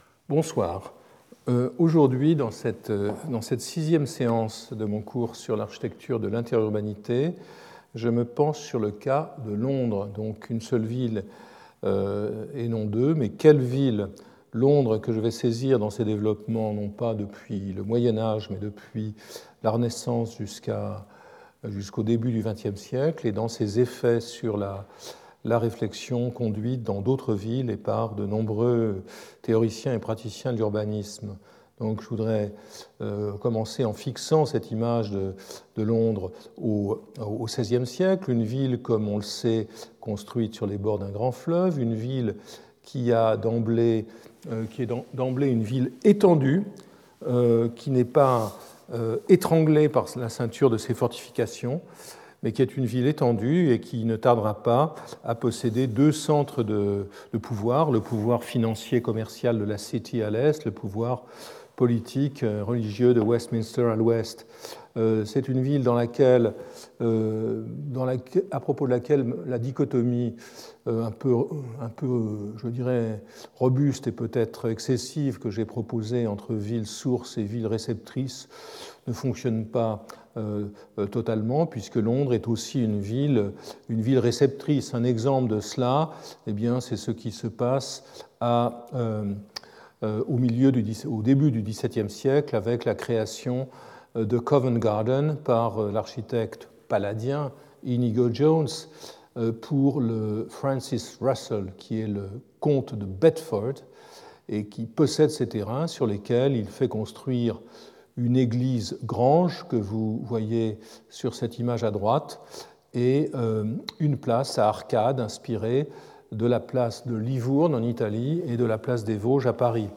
Jean-Louis Cohen Architecte, Professeur à l'Université de New York et au Collège de France